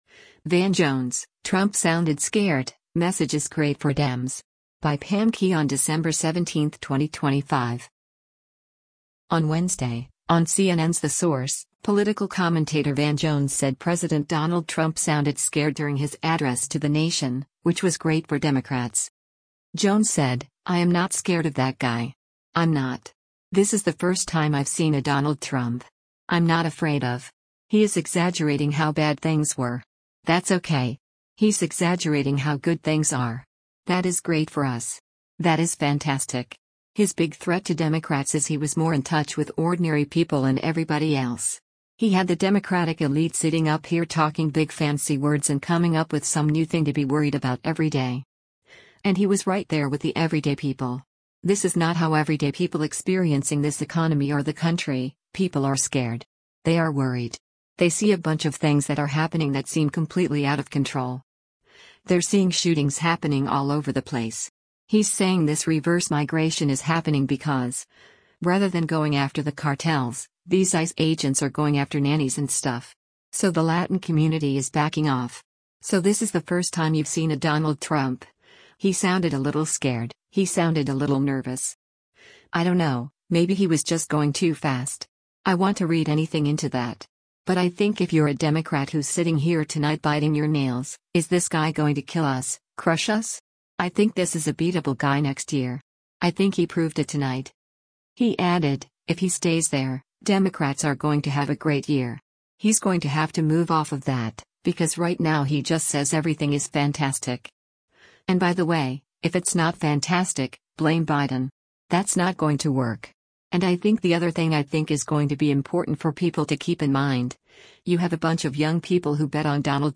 On Wednesday, on CNN’s “The Source,” political commentator Van Jones said President Donald Trump sounded “scared” during his address to the nation, which was great for Democrats.